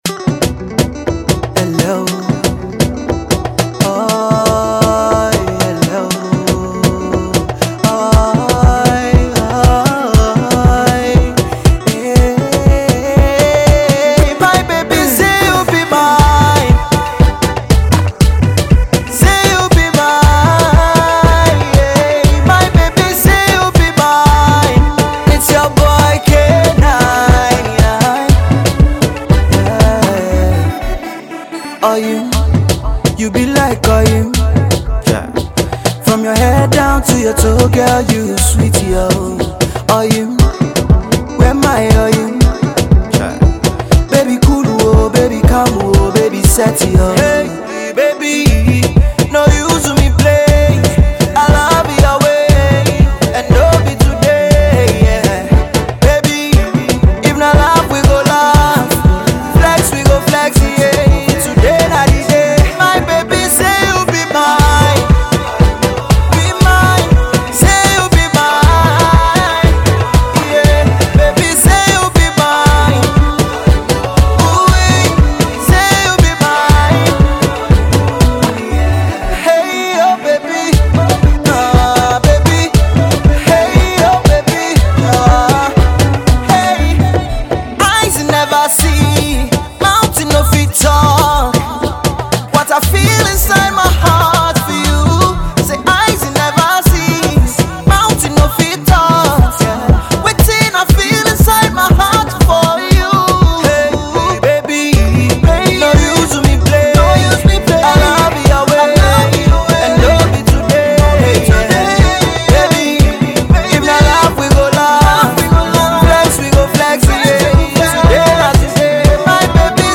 romantic single